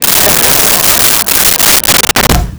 Crowd Laughing 06
Crowd Laughing 06.wav